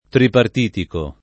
tripartito [tripart&to] part. pass. di tripartire e agg. («diviso in tre»; «di tre partiti») — nell’ultimo sign., anche s. m. («alleanza di tre partiti»), col relativo agg. tripartitico [